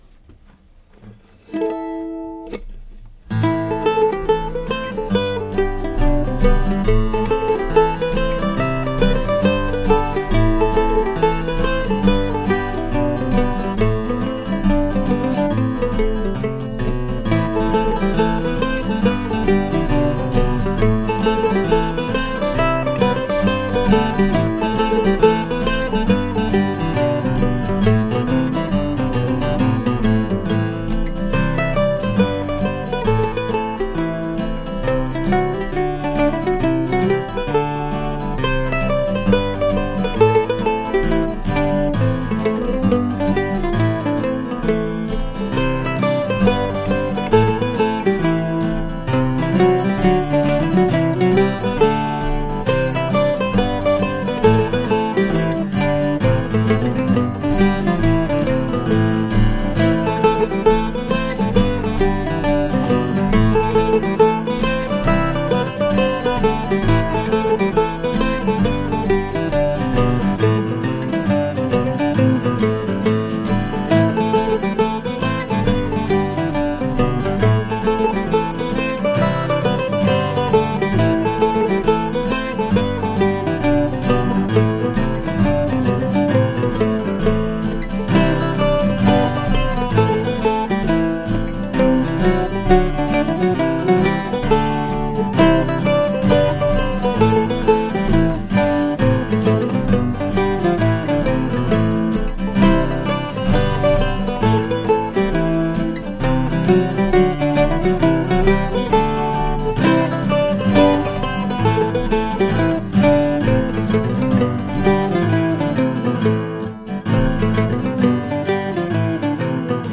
August 2, 2013 ( mp3 ) ( pdf ) A hornpipe in A minor from a couple of weeks ago.
The curious push-pull effect between the guitar and the mandolin is the result of recording the melody first and then adding the guitar chords after.